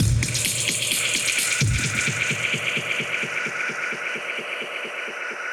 Index of /musicradar/dub-designer-samples/130bpm/Beats
DD_BeatFXB_130-02.wav